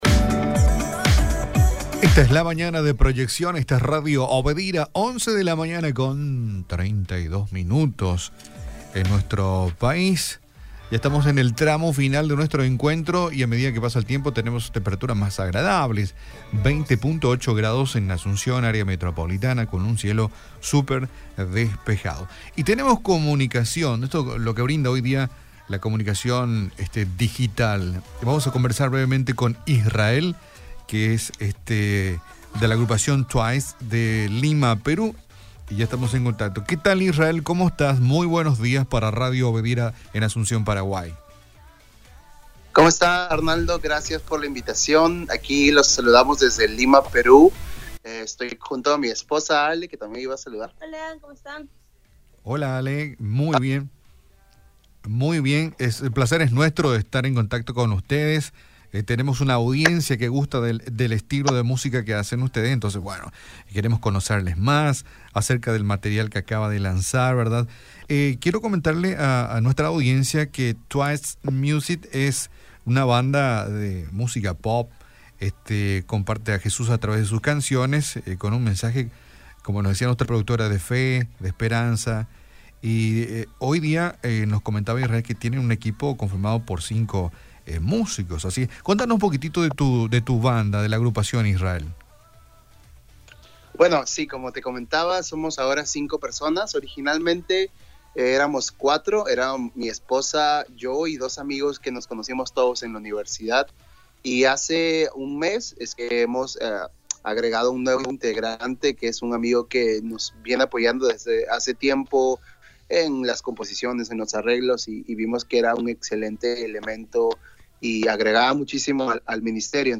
CONTACTO-TELEFONICO-TWICE-MUSICA.mp3